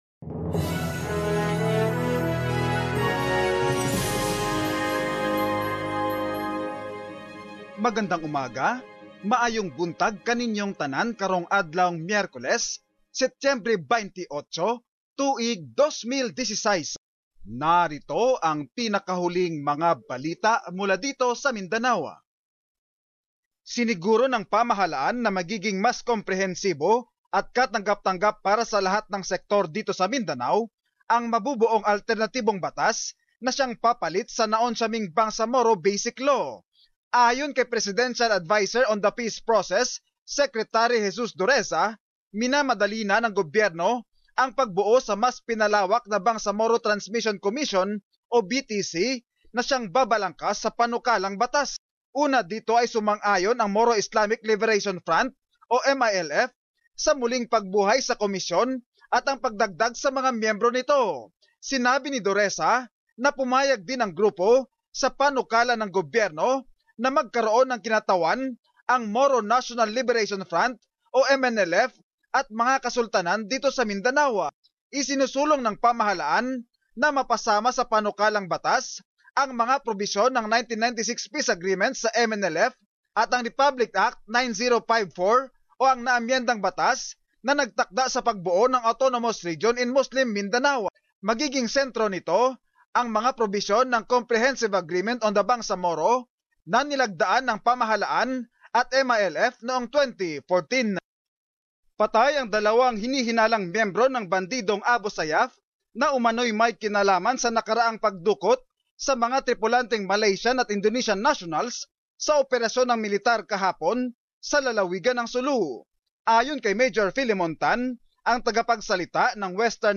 Summary of latest news in the region